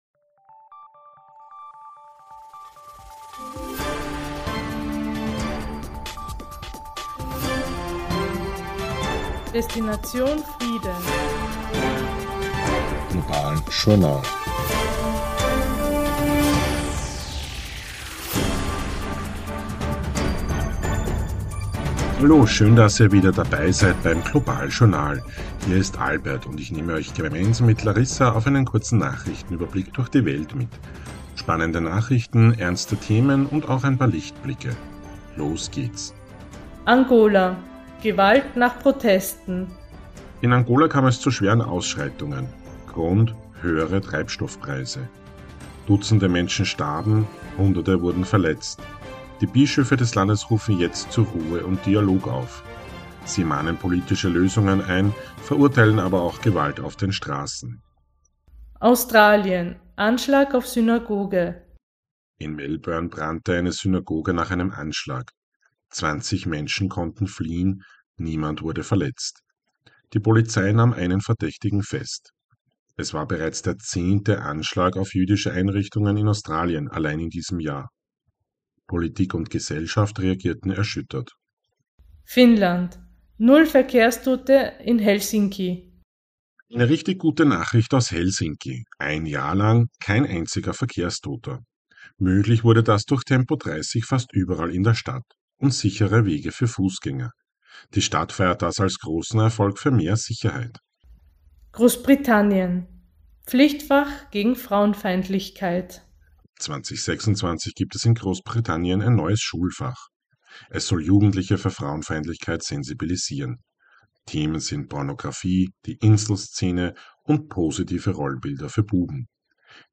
News Update September 2025